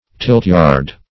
(formerly) an enclosed field for tilting contests ; The Collaborative International Dictionary of English v.0.48: Tilt-yard \Tilt"-yard`\, n. A yard or place for tilting.